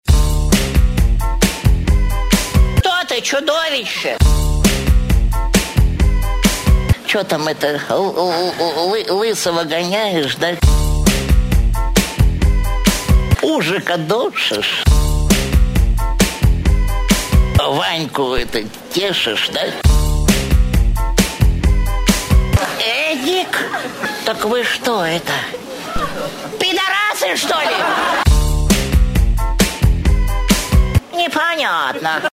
• Качество: 128, Stereo
веселые